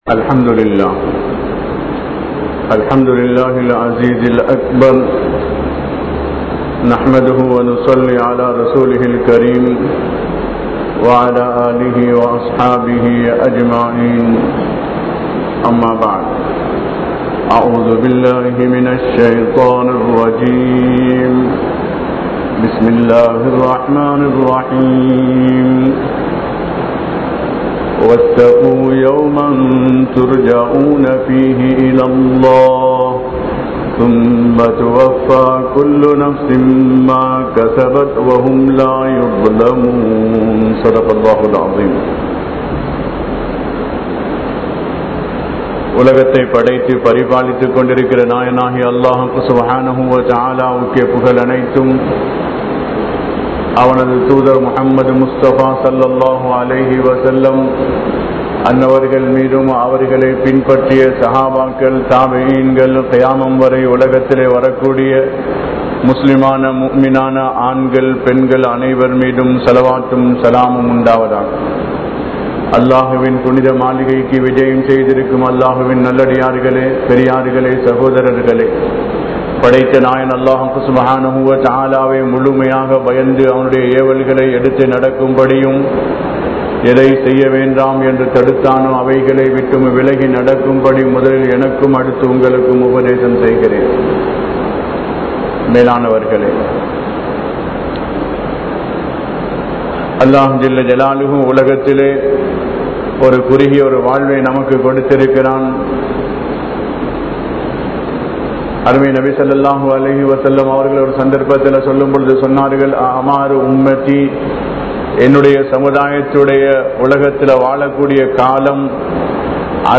Qiyamath Naalil Manithanin Nilai (கியாமத் நாளில் மனிதனின் நிலை) | Audio Bayans | All Ceylon Muslim Youth Community | Addalaichenai
Jumua Masjidh